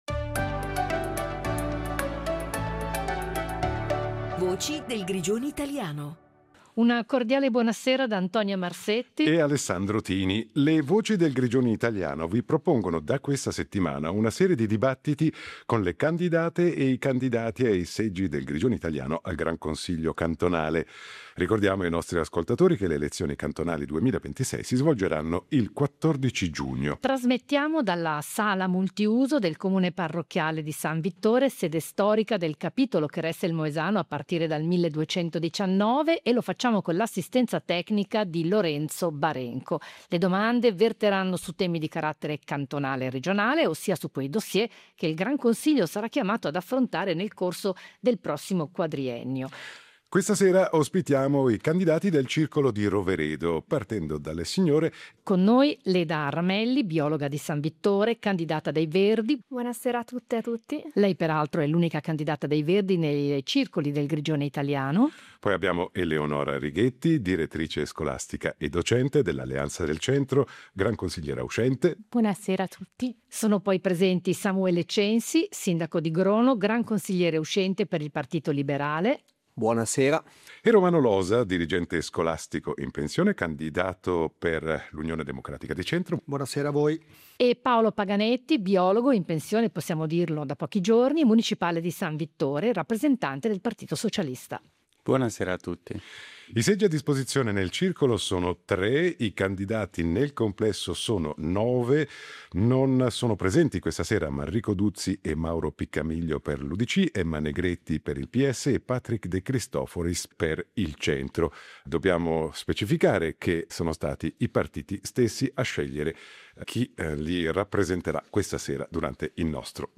I dibattiti elettorali in vista delle elezioni GR2026 – Il confronto tra i candidati rappresentanti i partiti che si contengono i tre seggi in Gran Consiglio del Circondario